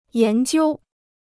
研究 (yán jiū): research; a study.